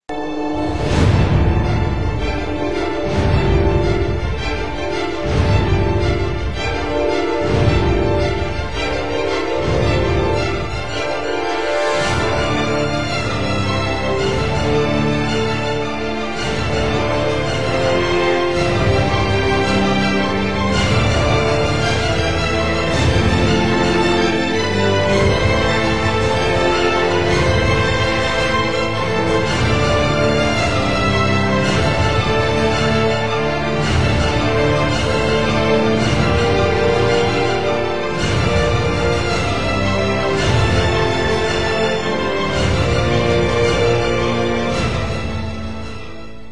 Jo, die Datei habe ich mir mal aus einem Musikstück gebastelt, soweit so gut, aber aus welchem?
Unter Garantie ist es aus einem Film-Soundtrack, aber ich komme nicht drauf.
Das Stück klingt vertraut, aber ich kann es nicht wirklich zu ordnen, zum Haare raufen!